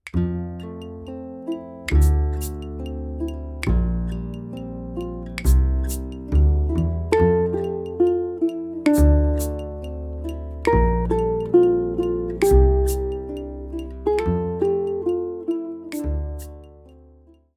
Betty Lou of '52 begins with a two-measure vamp: count to four twice before beginning the melody.
Betty_Lou_of_52_vamp.mp3